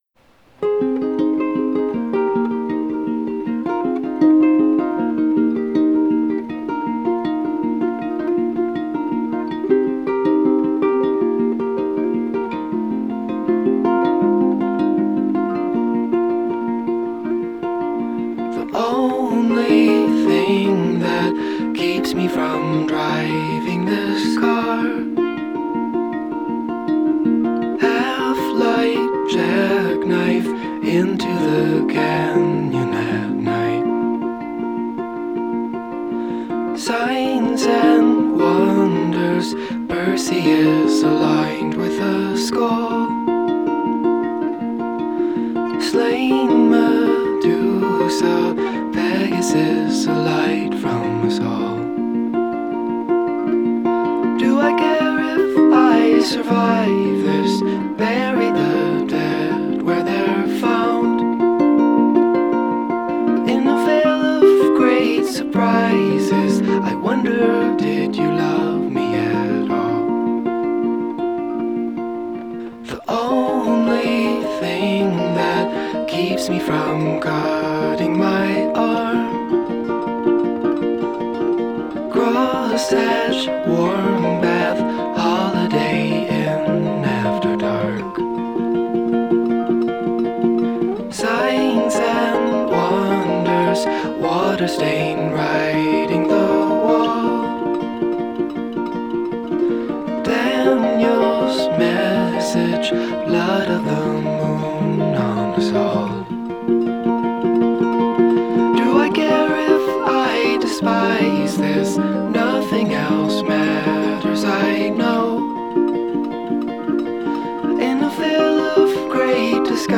Genre: Indie Folk, Singer-Songwriter